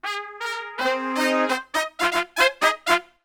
FUNK2 FM.wav